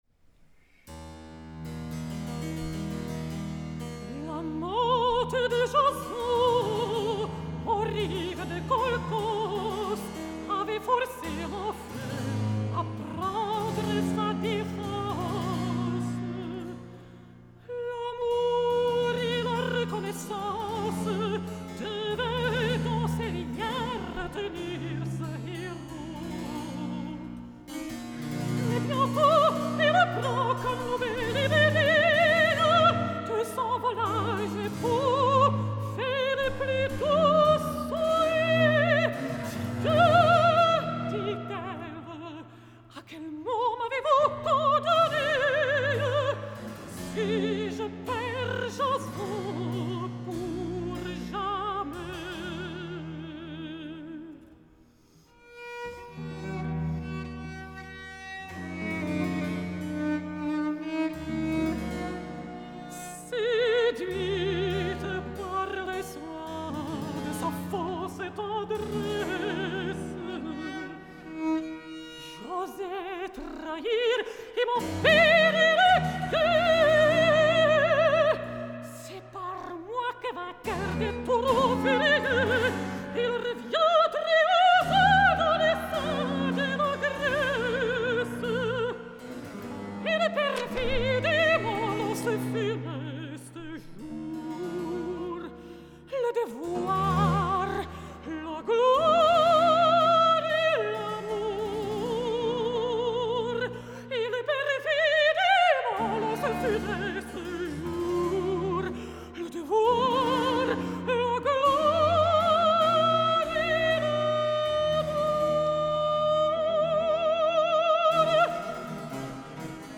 Avui us proposo un reguitzell de perles del barroc francès, les darreres a l’entorn del personatge de Medea i totes elles protagonitzades per la mezzosoprano Magdalena Kožená, acompanyada per Le Concert d’Astrée dirigit des del clavecí per Emmanuelle Haïm.
L’encanteri sonor que suposa aquest deliciós concert celebrat el 26 de juny al castell de Valtice a la República Txeca us permetrà escoltar algunes composicions i compositors poc sovintejats, quelcom que sempre és d’agrair.
La riquesa expressiva, estilística, musical i vocal de la millor Kožená estic segur que us captivarà, fins i tot si aquest repertori no és el que més us satisfà.